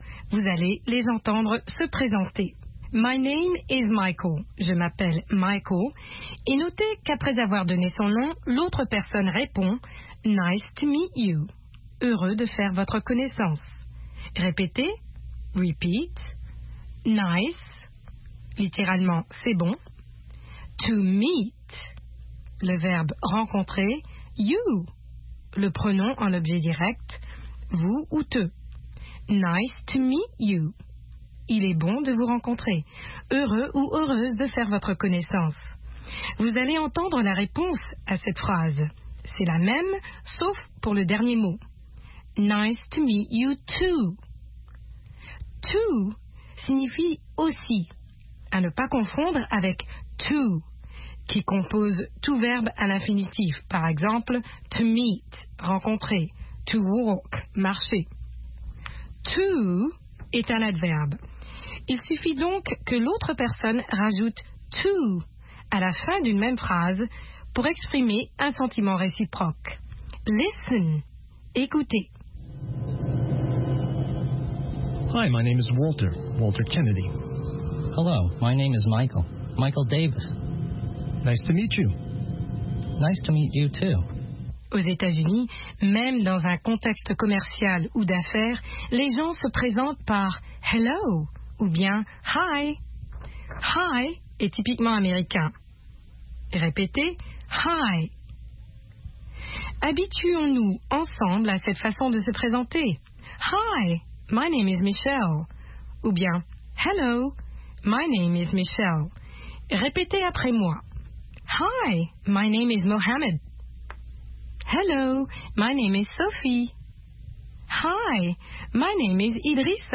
Des conversations en anglais américain par des Africains, qui ont trait à la vie quotidienne au Sénégal.